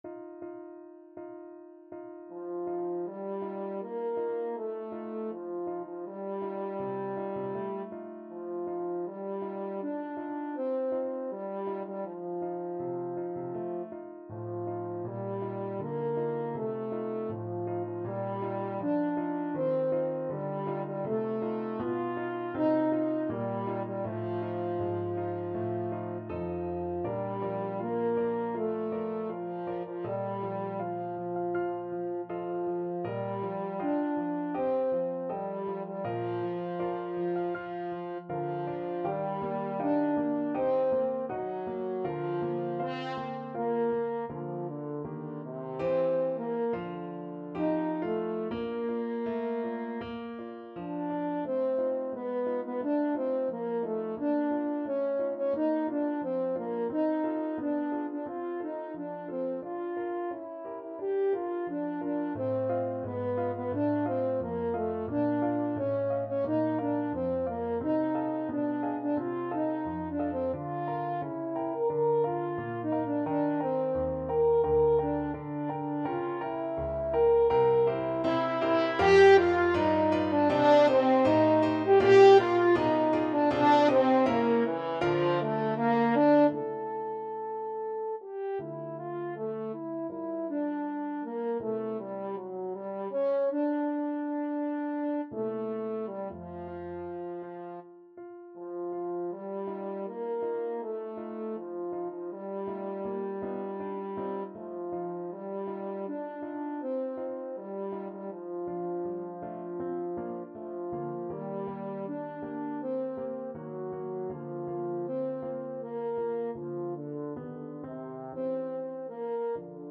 Andante espressivo
4/4 (View more 4/4 Music)
Classical (View more Classical French Horn Music)